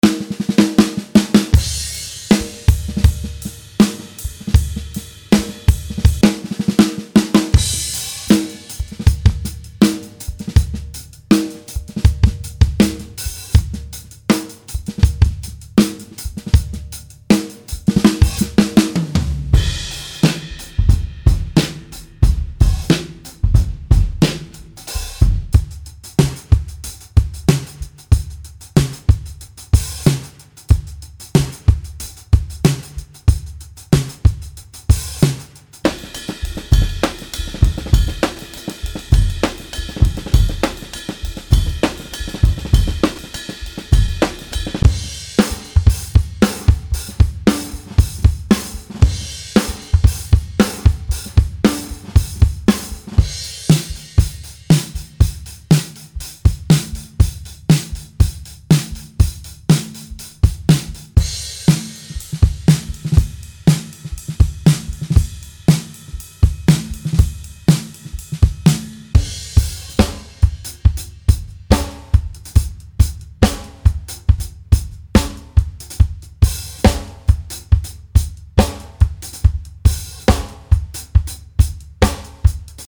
This pack features 56 dry, acoustic drum loops including rhythms and fills and ranging from 80 to 140 BPM.
These loops are perfect for Hip Hop, Funk, Soul and Acoustic tracks and have been recorded using high end drums and Cymbals.